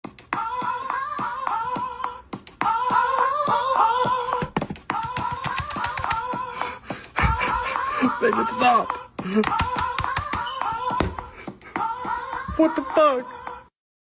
Oh oh oh oh oh sound effect
u3-Oh-oh-oh-oh-oh-sound-effect.mp3